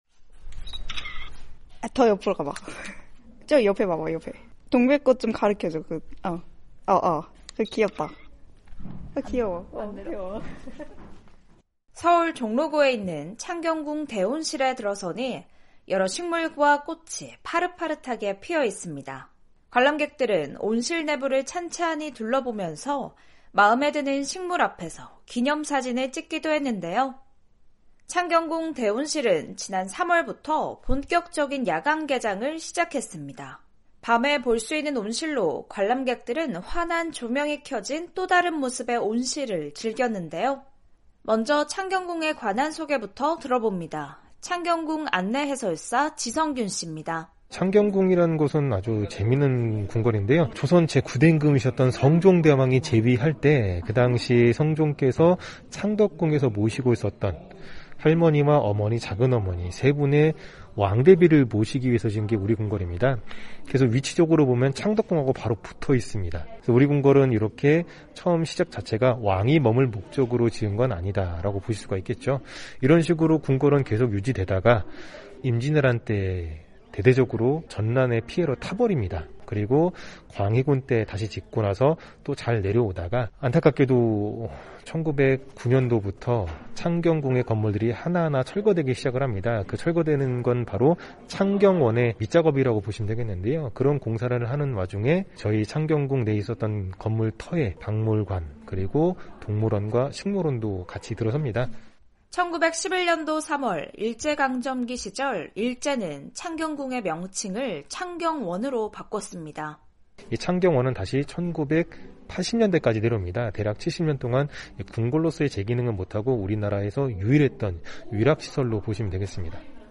궁 안에서 볼 수 있는 서양식 식물원으로 많은 관람객의 발길을 이끌고 있다고 합니다. 변화하는 한국의 모습을 살펴보는 ‘헬로서울’, 오늘은 '창경궁 대온실' 현장으로 안내해드립니다.